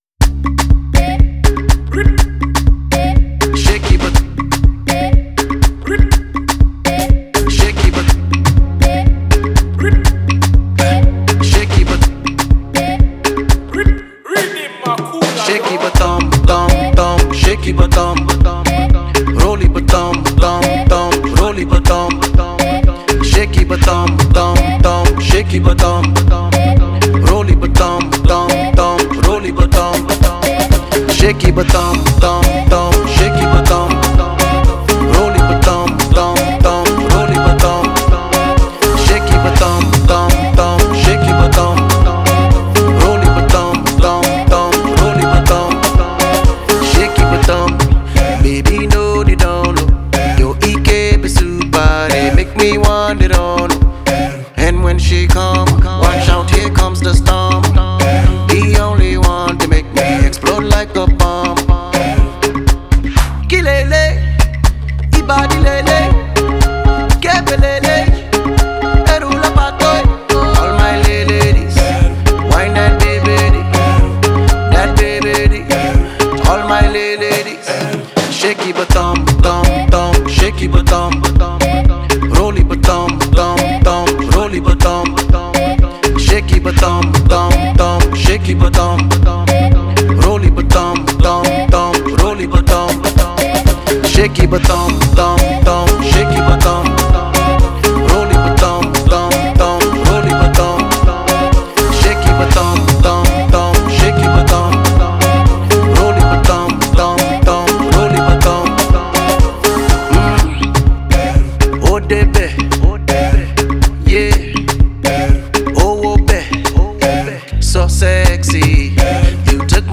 это трек в жанре R&B и хип-хоп